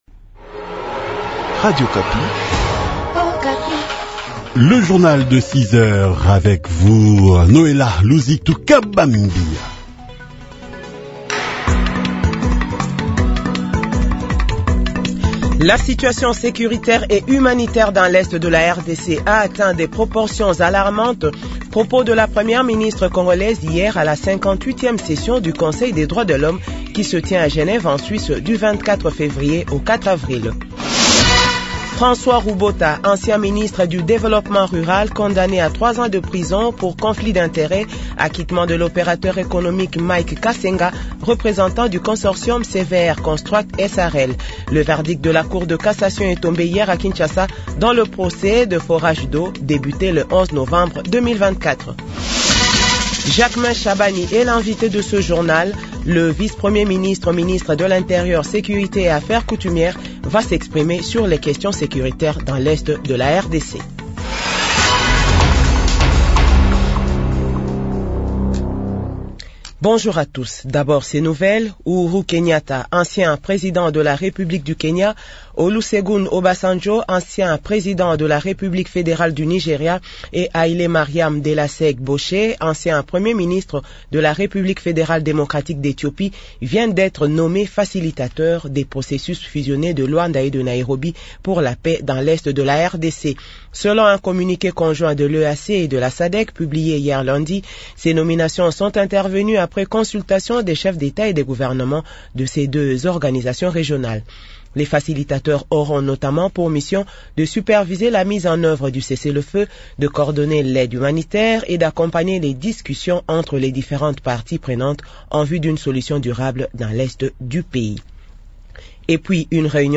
Journal 6h